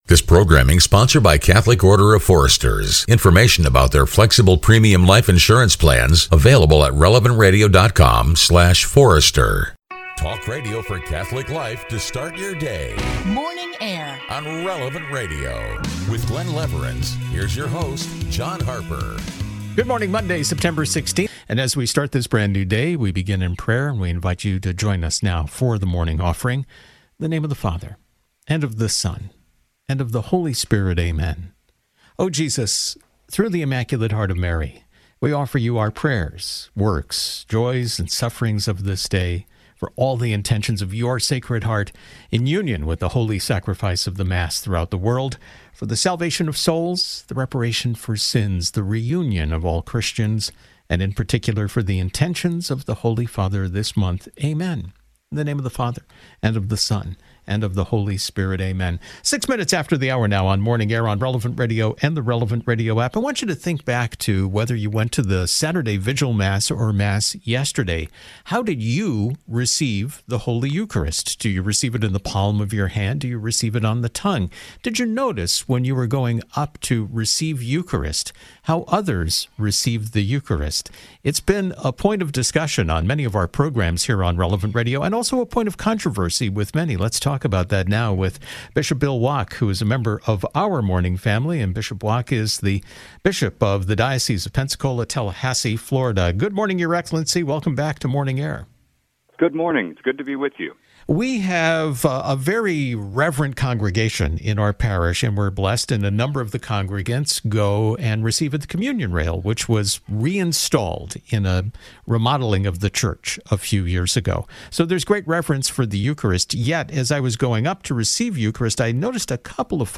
Bishop Bill Wack of the Diocese of Pensacola-Tallahassee stopped by Morning Air® recently to share his guidance on how to properly receive Our Lord in the Eucharist, and broke it down into three easy steps.